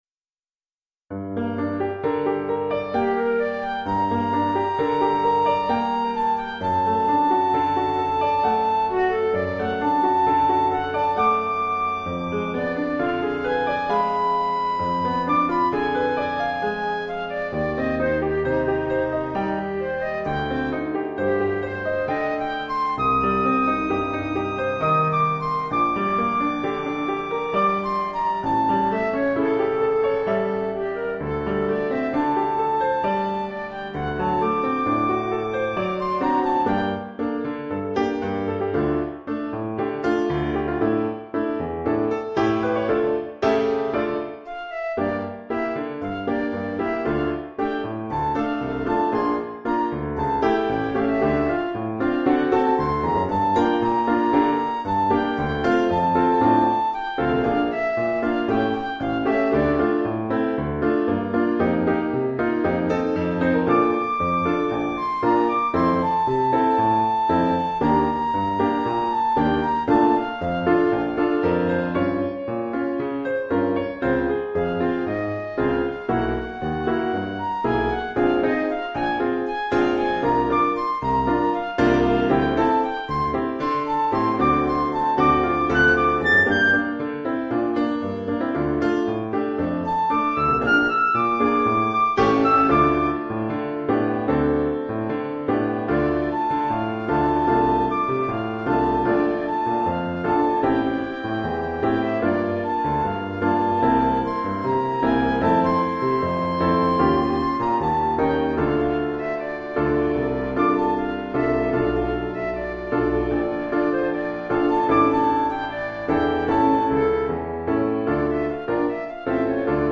1 titre, flûte et piano : conducteur et partie de flûte
Oeuvre pour flûte et piano.
au pays du tango et de la milonga